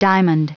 Prononciation du mot diamond en anglais (fichier audio)
Prononciation du mot : diamond